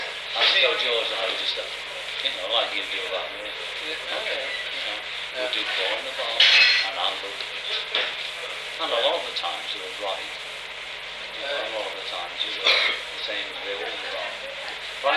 Instead, I think John clearly says “four in a bar,” as in the rhythm.
Here’s that line on the Nagra tapes in its original context:
While this has long been called the lunchroom tape, we don’t actually hear anyone dining; the clatter of cutlery is from the staff working in the cafeteria.
4-in-the-bar.mp3